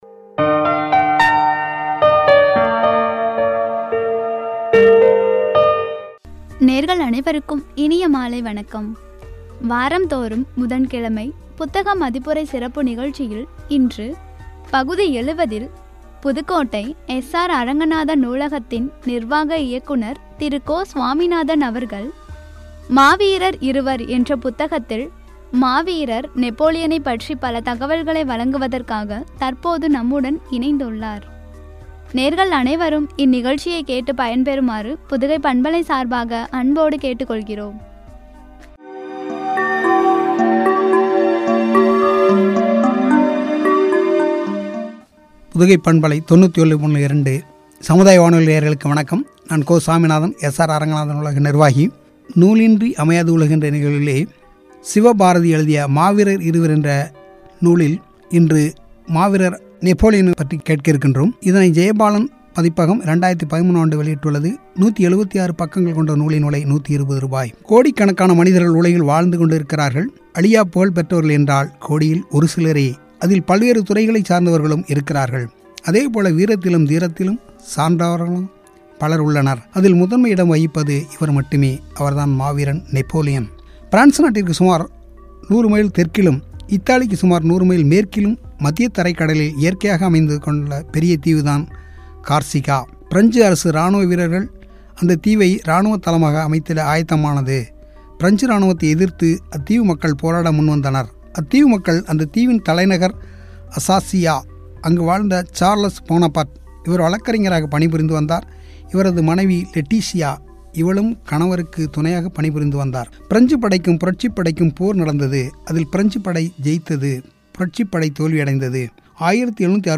” புத்தக மதிப்புரை (பகுதி – 70), குறித்து வழங்கிய உரை.